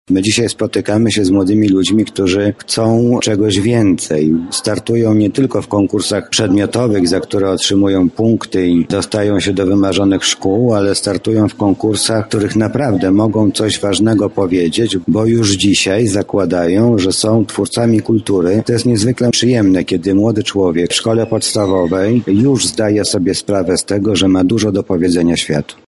Zapytaliśmy Zastępcę Prezydenta ds. Oświaty i Wychowania, Mariusza Banacha, jak młodzi uczniowie wpisują się w definicję ,,Miasta Kultury”: